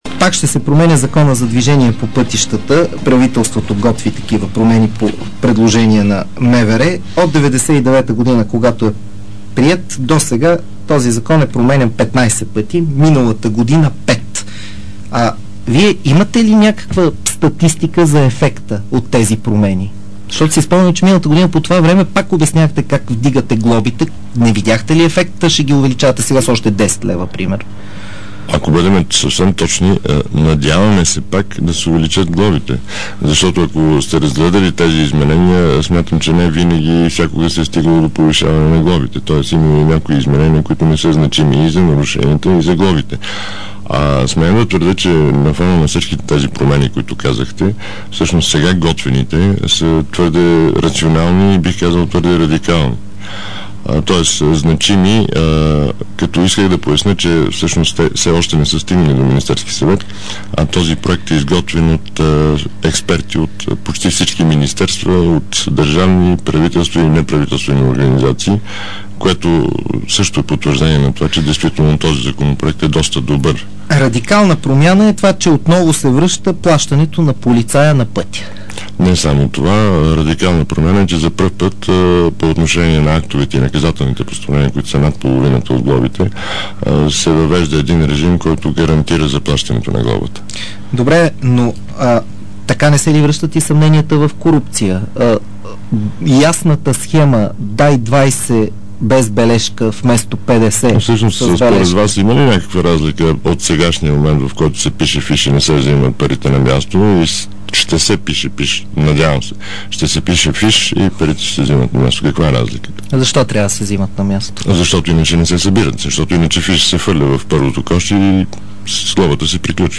DarikNews audio: Полк. Алекси Стратиев, директор на КАТ–Пътна полиция в предаването